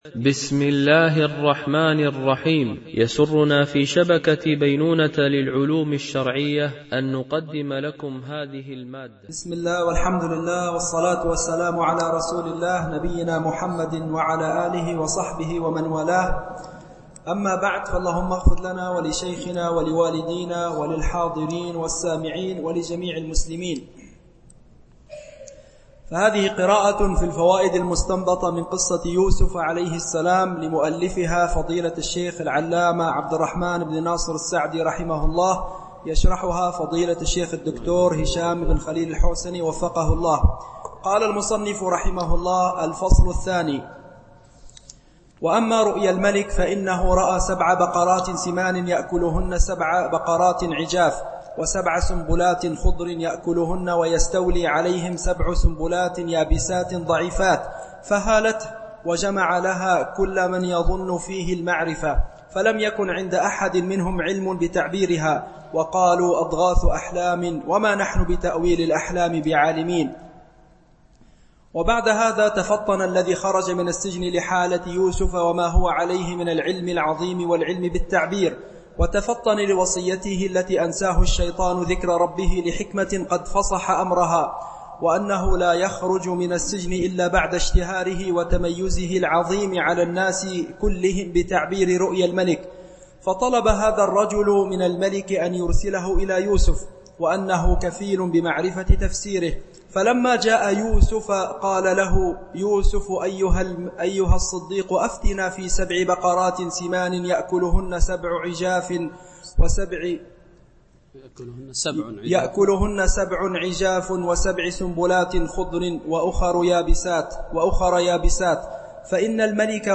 دورة علمية شرعية: لمجموعة من المشايخ الفضلاء، بمسجد عائشة أم المؤمنين - دبي (القوز 4)